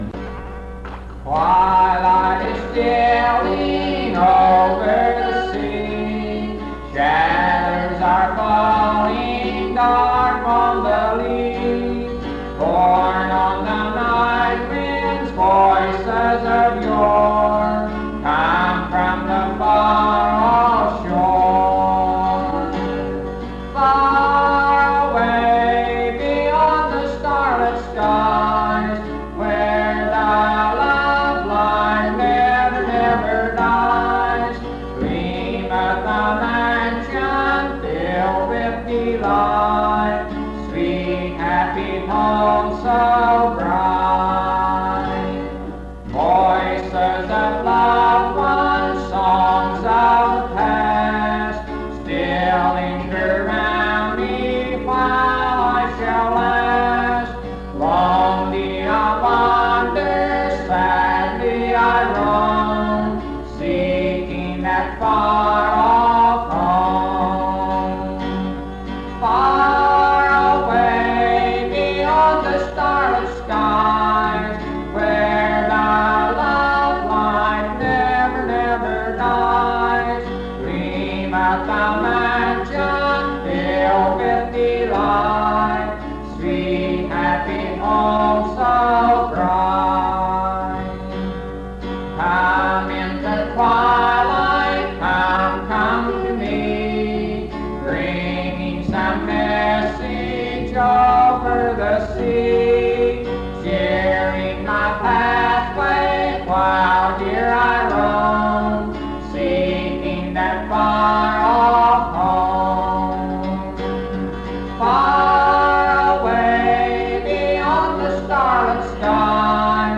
This recording is from the Monongalia Tri-District Sing. Church of the Bretheren, Morgantown, Monongalia County, WV, track 138K.